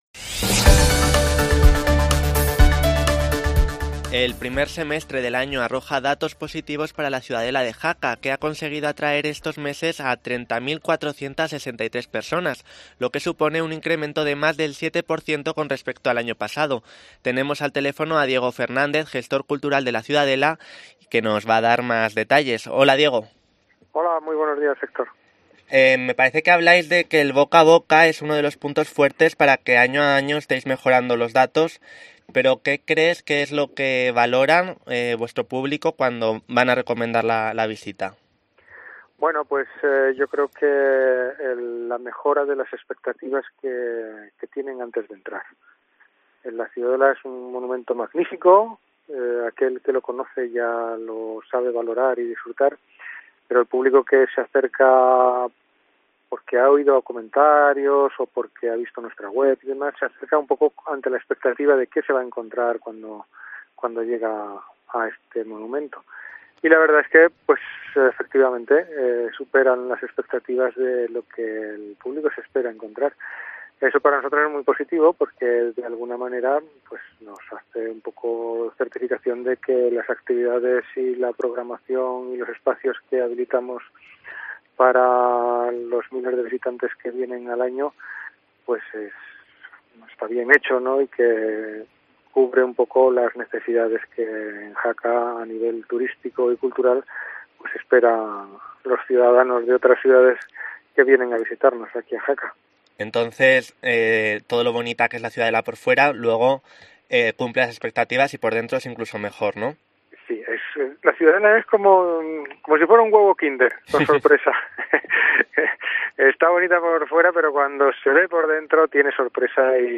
La Mañana en Jaca Entrevista